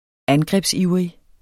Udtale [ ˈangʁεbs- ]